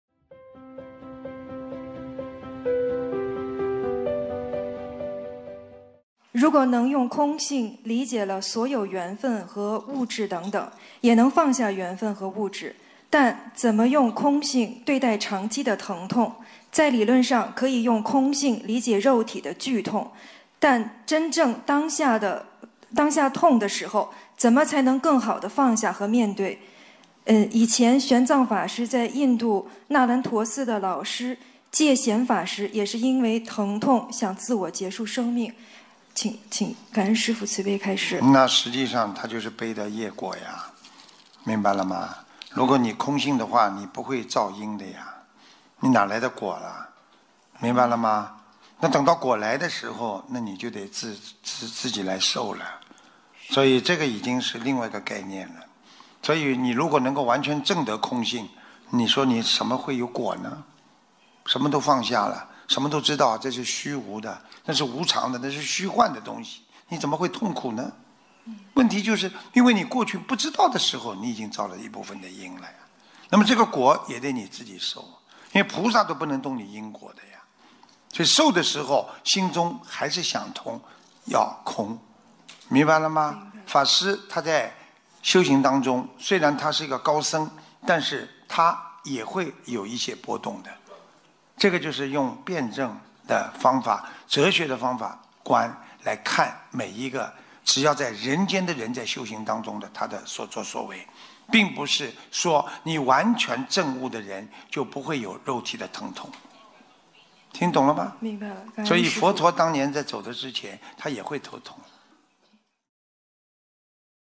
音频：能否用空性看待肉身的疼痛！巴黎弟子开示共修组提问节选！